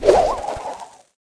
dynamike_throw_01.wav